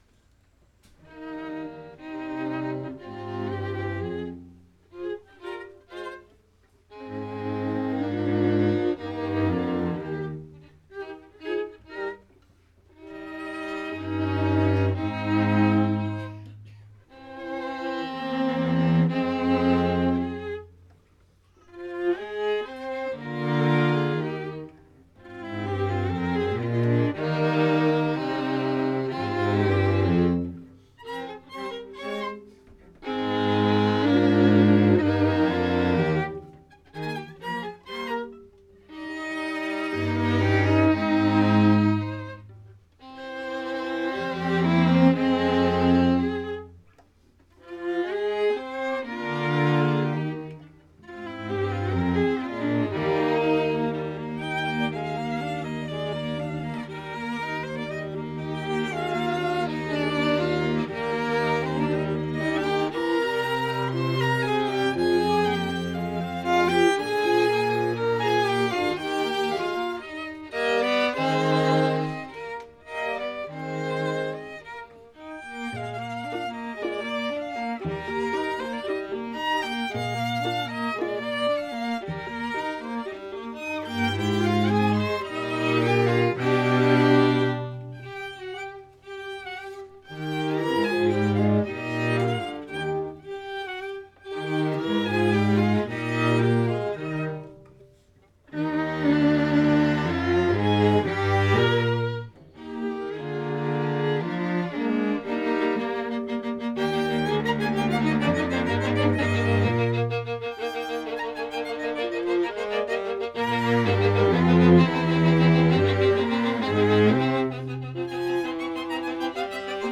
2:00 PM on July 20, 2014, "Music with a View"
Allegro moderato